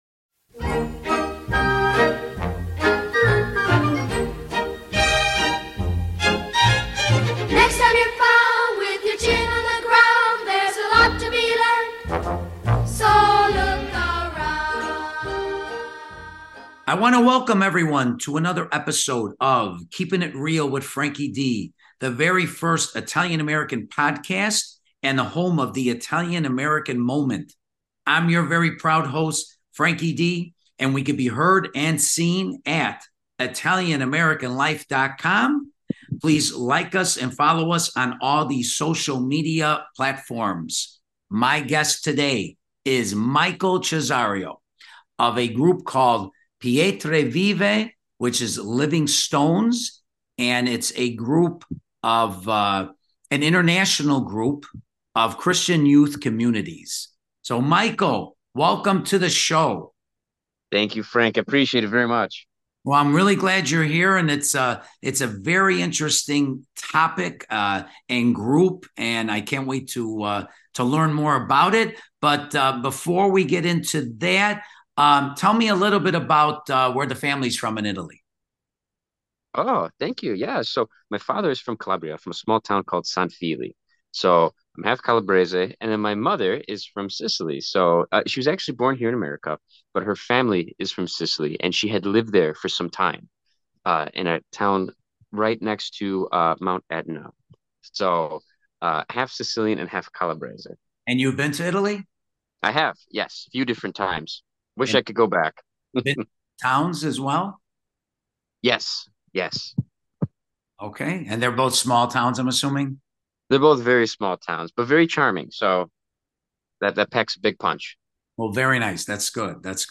Interview